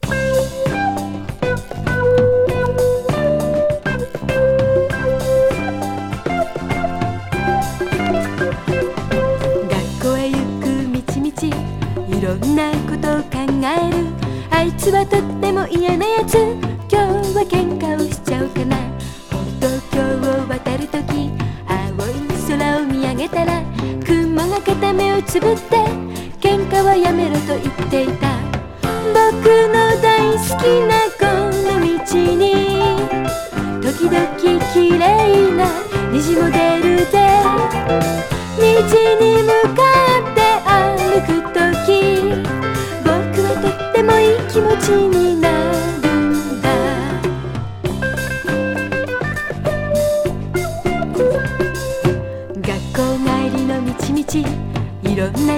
JAF交通安全キャンペーンソング。
ダンサブル・ポップ・チューン！